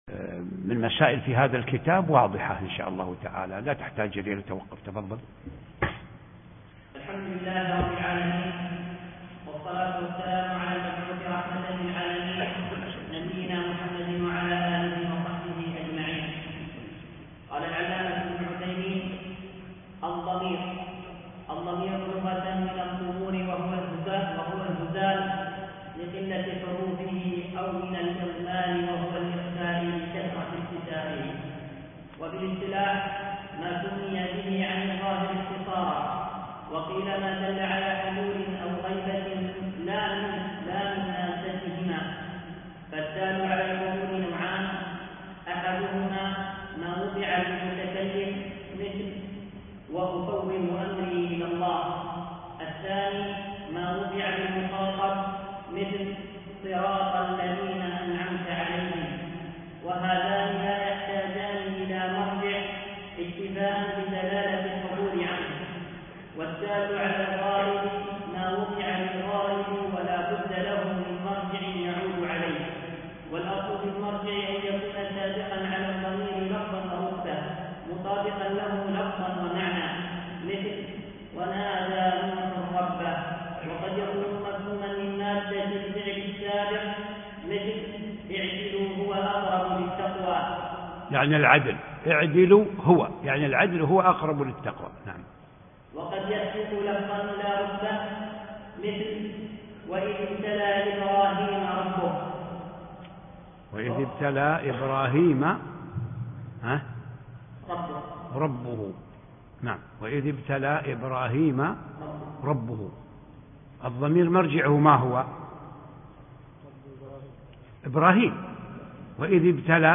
الدرس 5